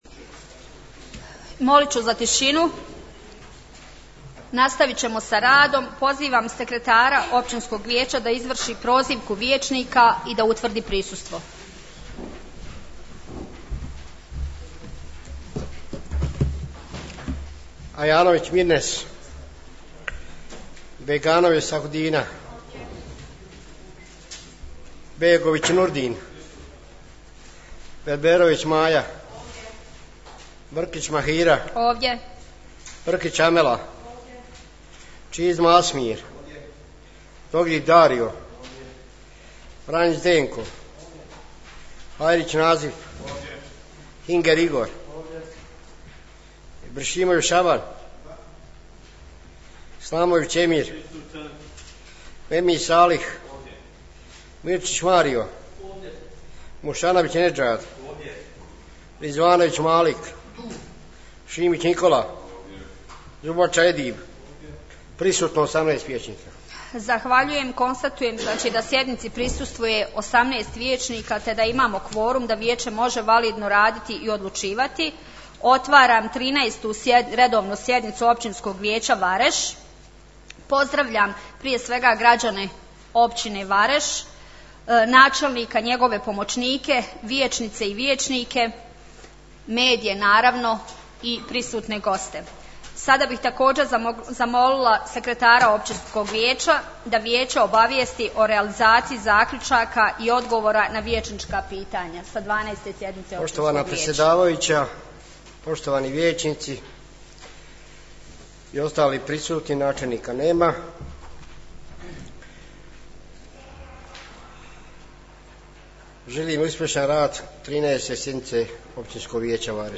U dvorani Općine Vareš 28.02.2022. godine održana je 3. svečana sjednica u povodu 01. ožujka/marta Dana neovisnosti Bosne i Hercegovine i redovna 13. sjednica Općinskog vijeća.